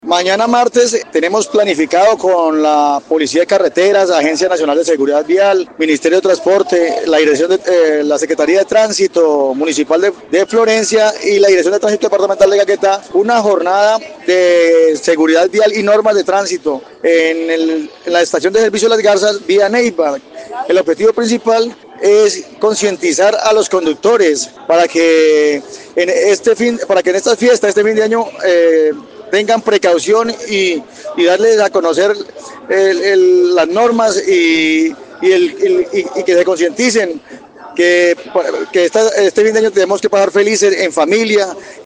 Hernán Castañeda, director de tránsito departamental, explicó que junto a policía de Carreteras y la agencia nacional de seguridad vial, harán énfasis en acciones de seguridad y prevención en carretera.